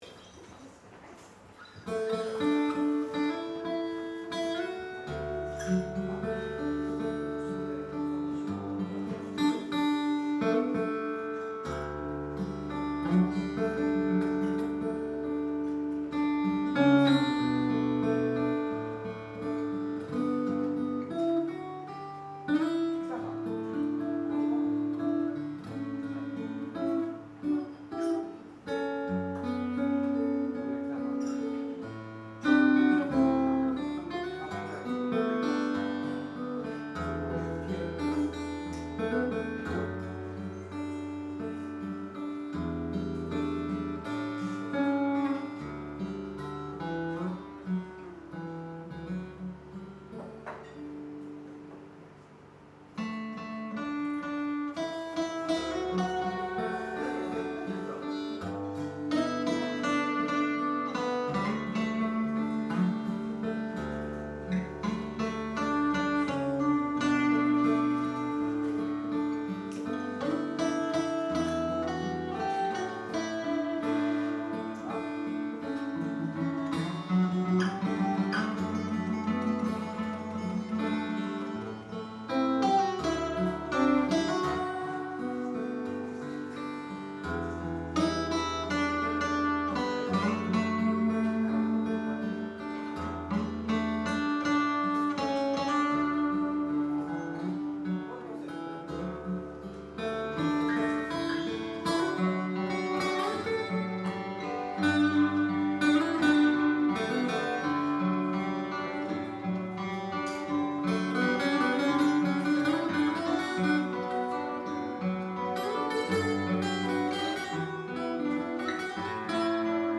アコギインスト・デュオ
tacoピン小を２つ貼って、デュアルで出力。
２ｃｈのプリアンプで、EQ＆MIXしてから、エフェクトボード。
↓実際のライブ演奏の録音です。
ウォーターイズワイド(mp3)（２回目のテーマがｔａｃｏピン）